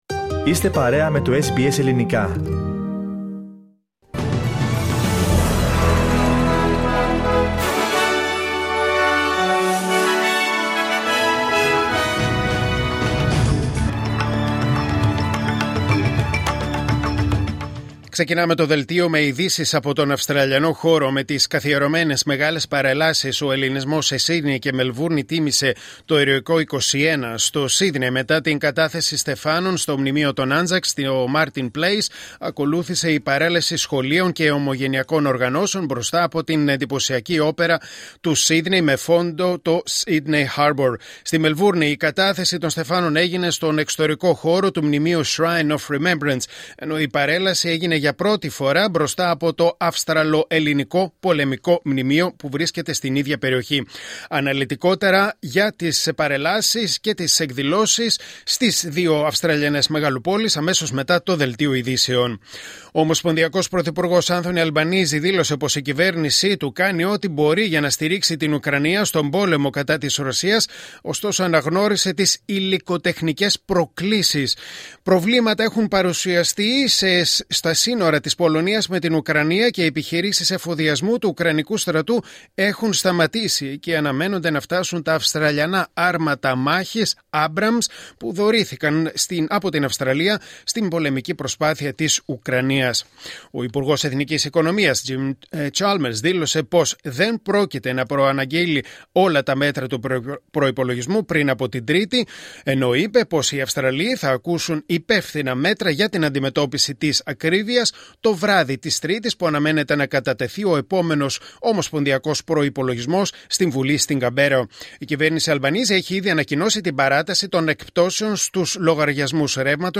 Δελτίο Ειδήσεων Κυριακή 23 Μαρτίου 2025